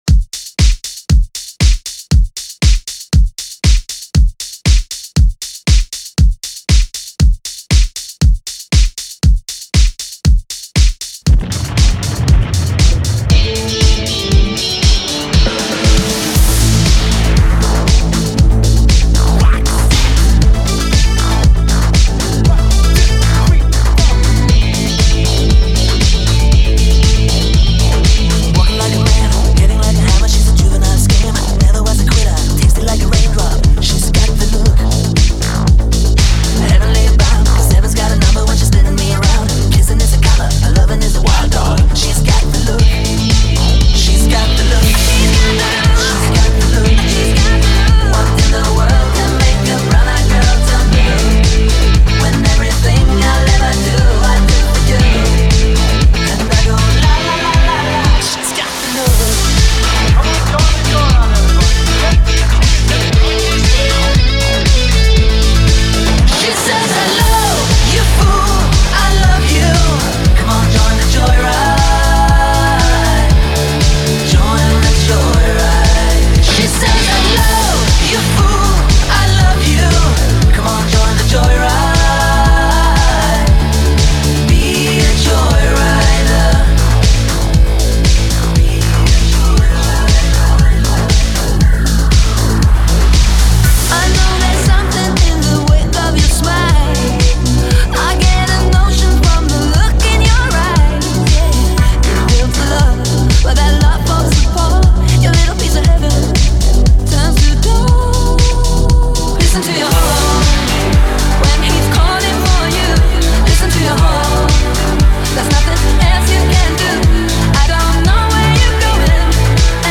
Relive the Classics with a Modern Miami Twist
high-energy house experience